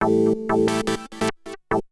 SYNTH029_PROGR_125_A_SC3(L).wav